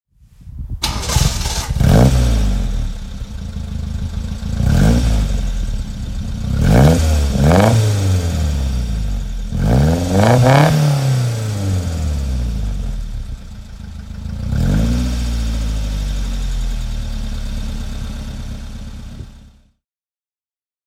Alfa Romeo Giulietta Sprint (1957) - Starten und Leerlauf
Alfa_Romeo_Giulietta_Sprint_1957.mp3